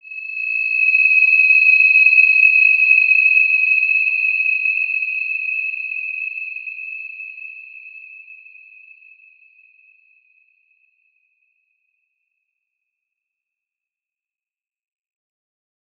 Wide-Dimension-E6-mf.wav